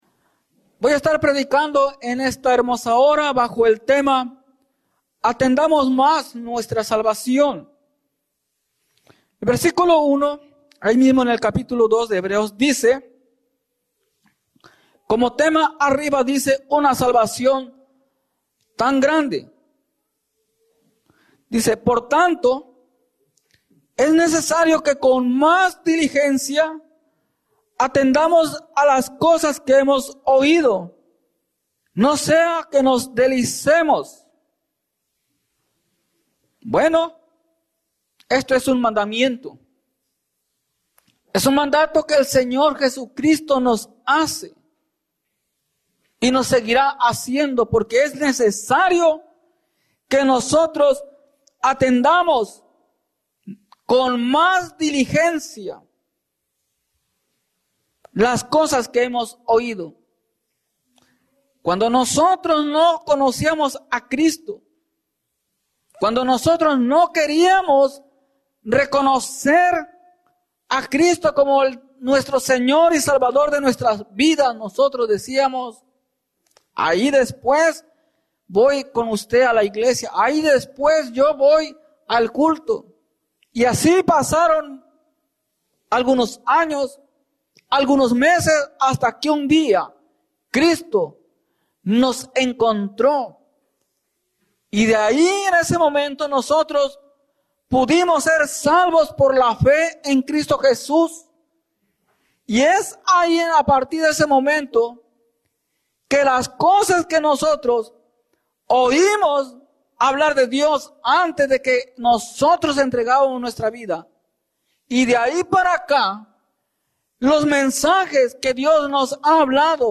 Atendamos más nuestra salvación Predica